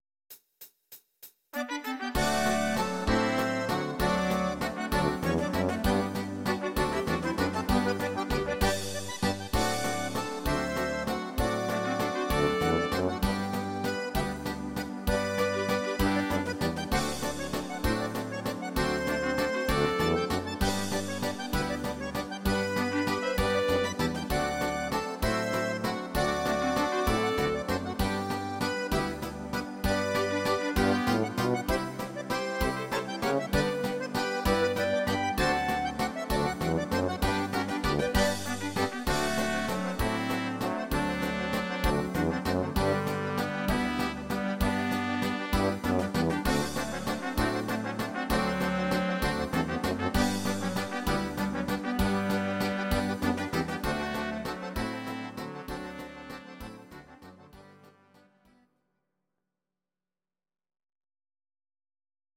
These are MP3 versions of our MIDI file catalogue.
Please note: no vocals and no karaoke included.
instr. Akkordeon